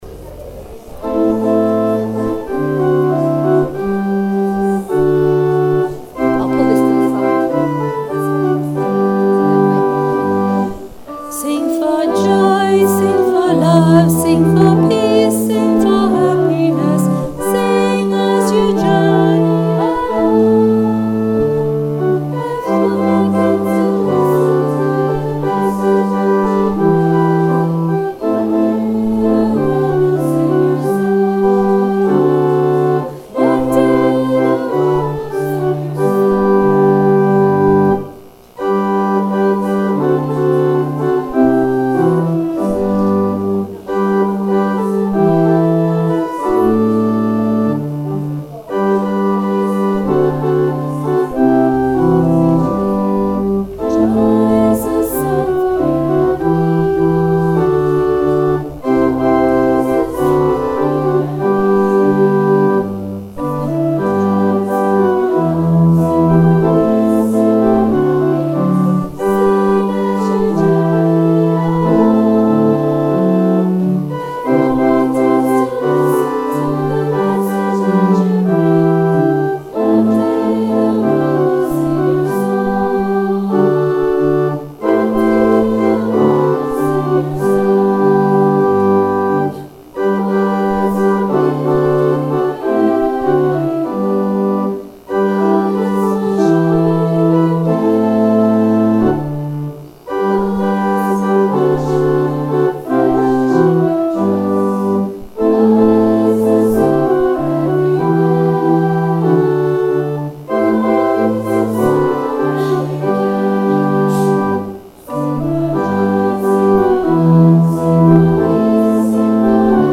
Thinking Day - 19 February 2019
We sang '